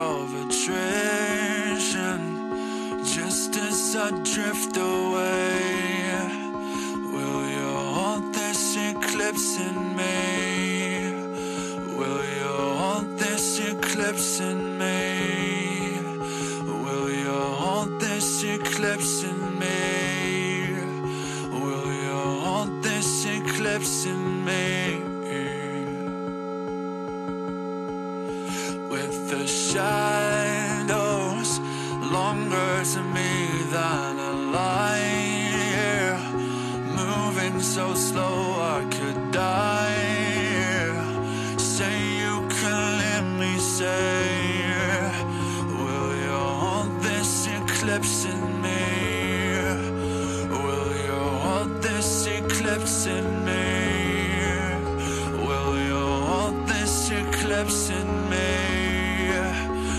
breakdown
Drum Cover